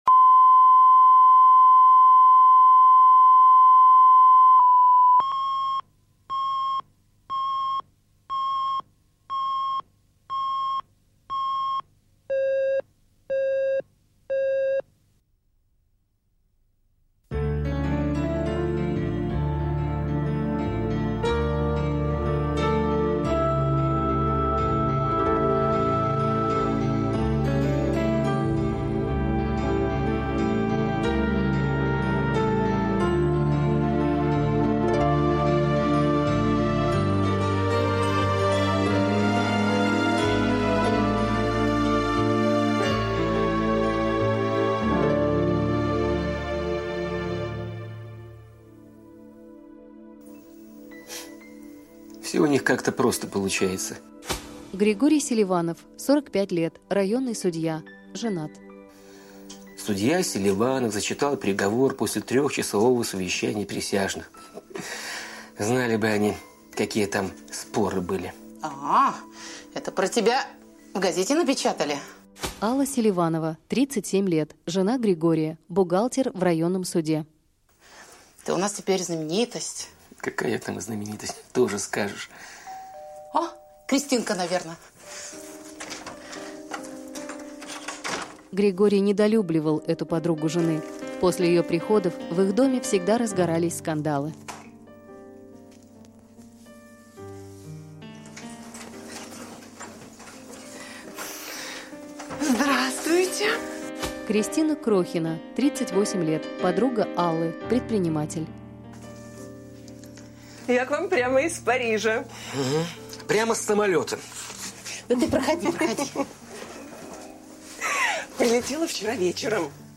Аудиокнига Ваша честь | Библиотека аудиокниг